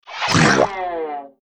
AA_throw_wedding_cake_miss.ogg